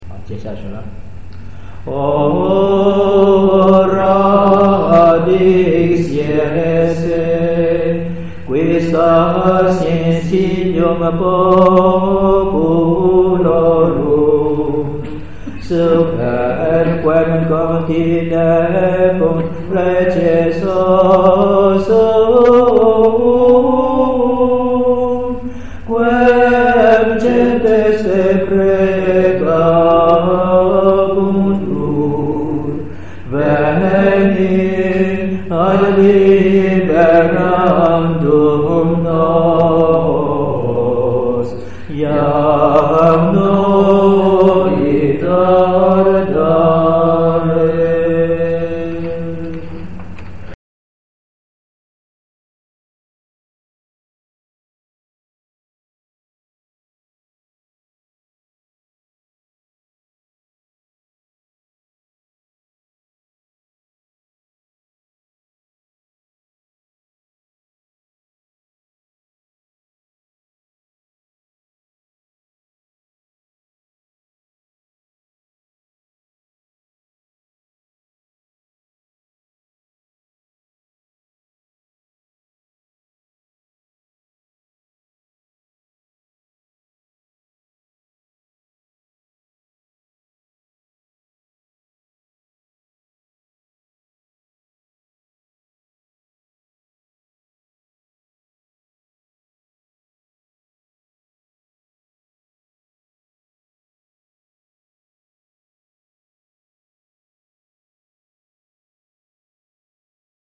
歌唱：OFM戊子年初學班 2008